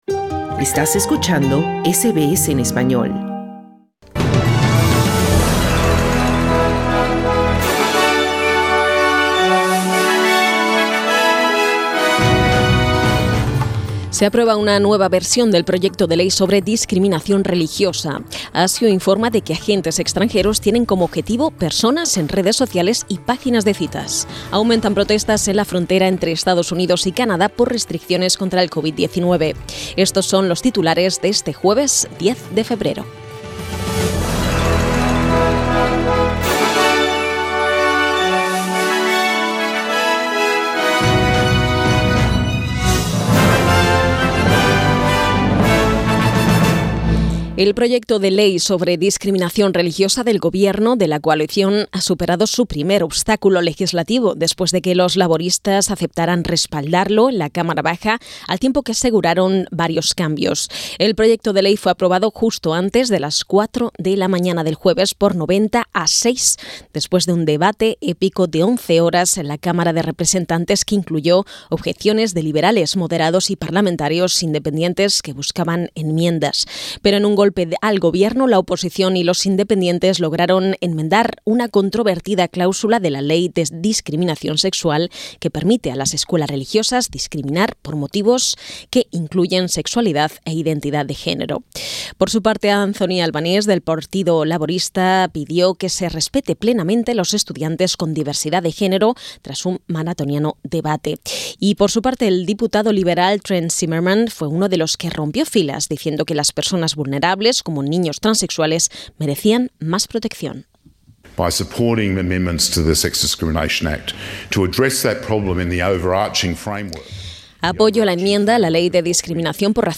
Noticias SBS Spanish | 10 de febrero 2022